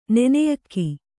♪ neneyakki